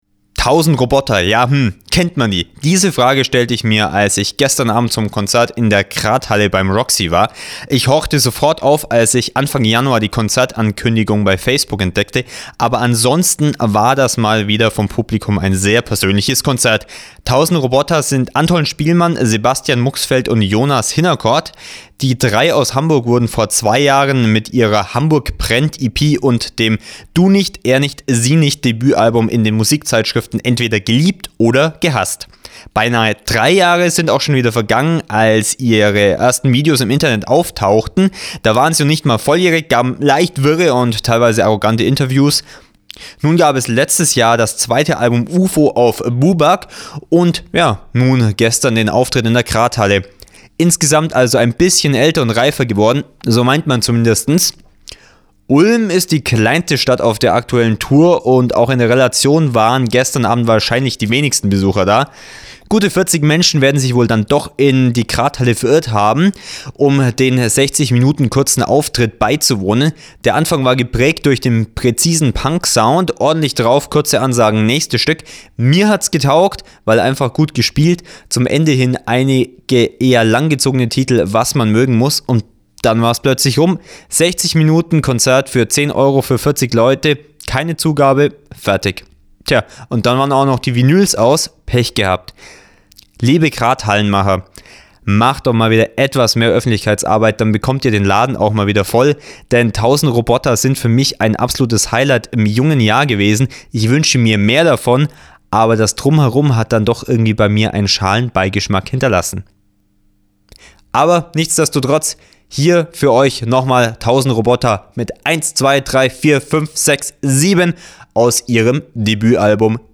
Review :: 1000 Robota